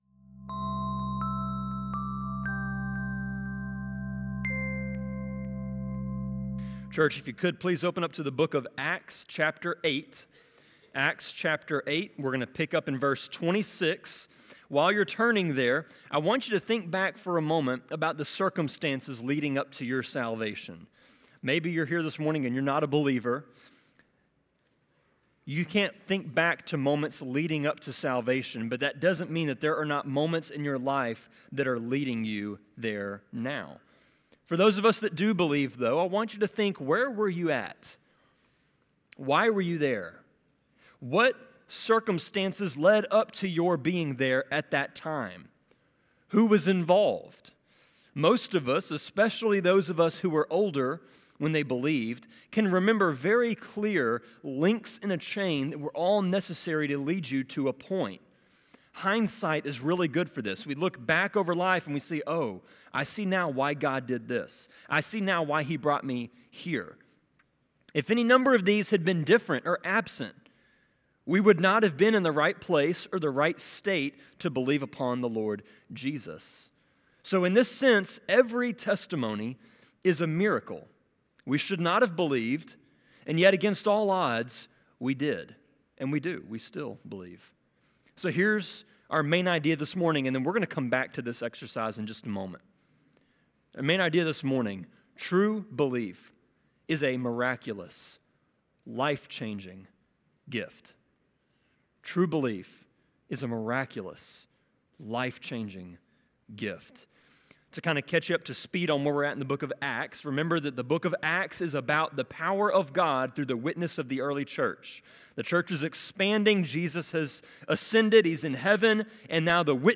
Sermon-24.4.14.m4a